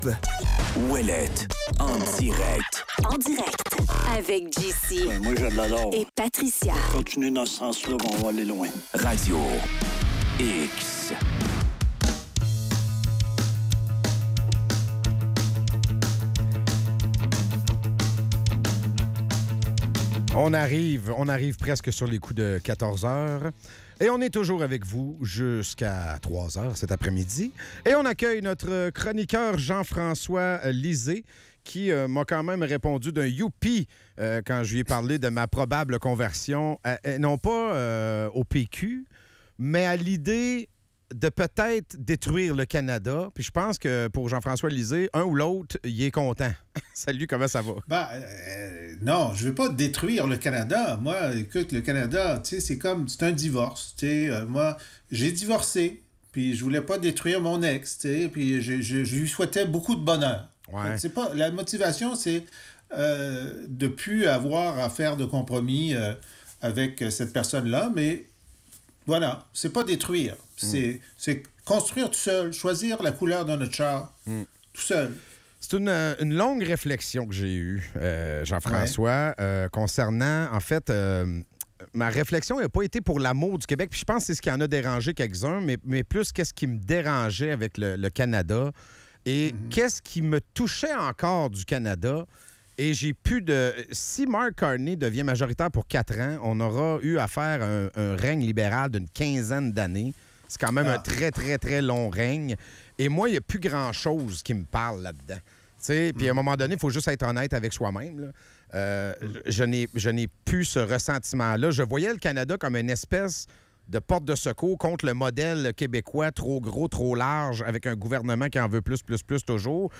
En chronique, Jean-François Lisée. Jean-François Lisée aborde la question de l'indépendance du Québec et la place de la droite dans le mouvement souverainiste. Il évoque son ressenti face au Canada et souligne l'importance d'avoir des voix de droite qui souhaitent l'indépendance, tout en critiquant le modèle économique actuel.